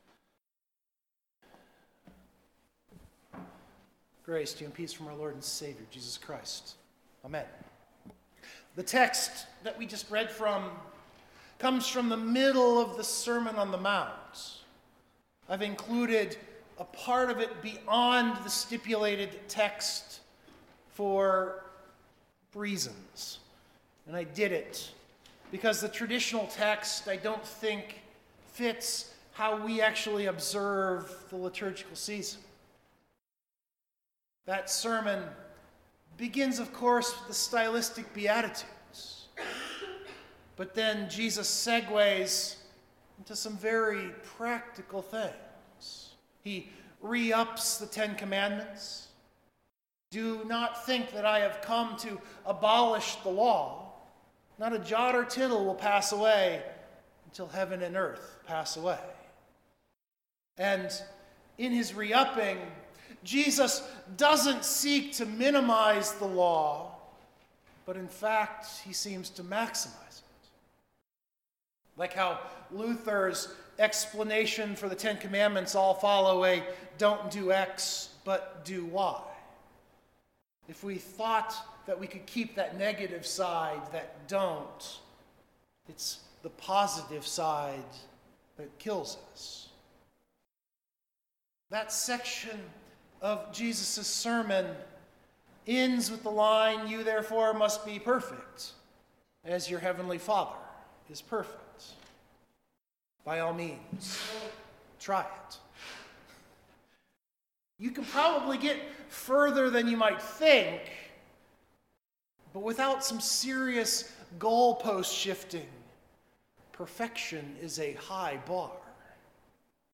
Then this sermon is for you.
Worship note: I’ve left in our final hymn of the day.